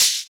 • Shaker Sound C Key 12.wav
Royality free shaker percussion tuned to the C note. Loudest frequency: 5891Hz
shaker-sound-c-key-12-PVS.wav